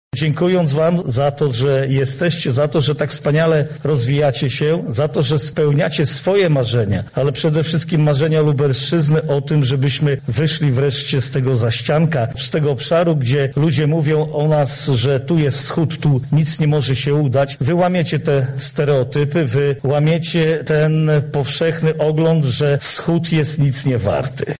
Za nami finał konkursu Lider Innowacji 2020.
Prowadzenie biznesu polega na ciągłym poszukiwaniu i ciągłym rozwoju – mówił marszałek województwa lubelskiego Jarosław Stawiarski: